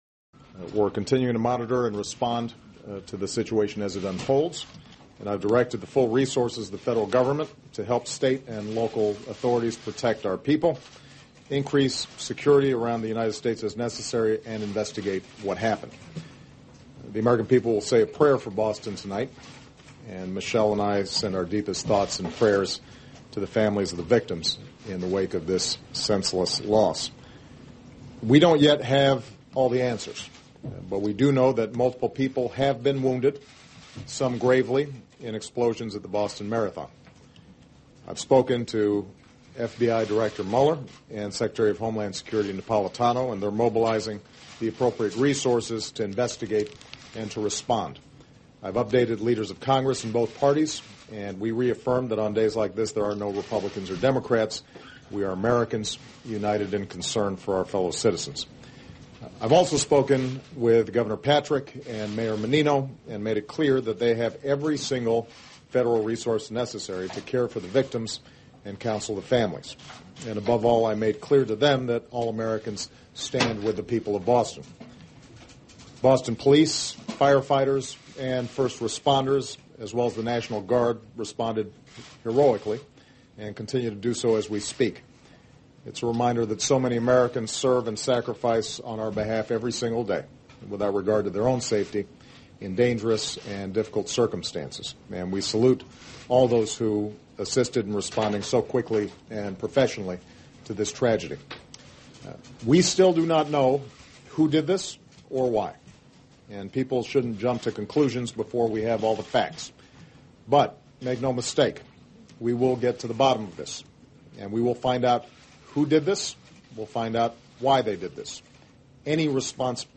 President Obama's Statement after the Boston Marathon Bombing